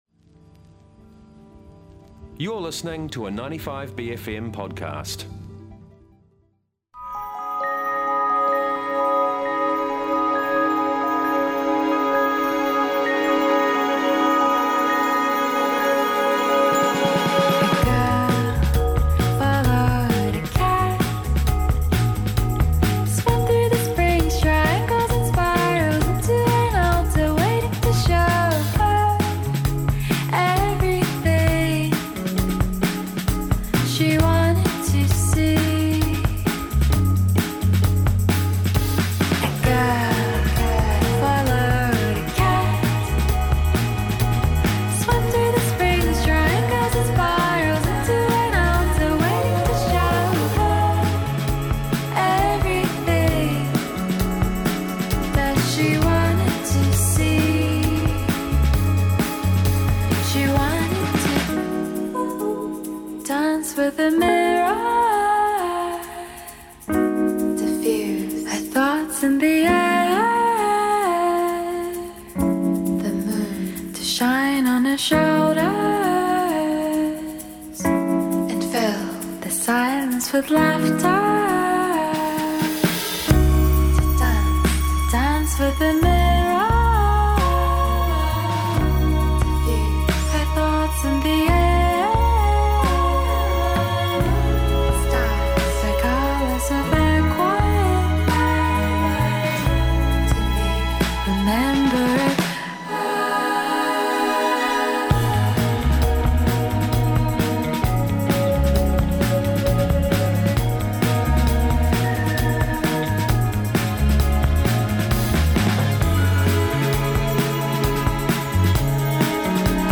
Guest Interviews
A simple feed of all the interviews from our many and varied special bFM Breakfast guests.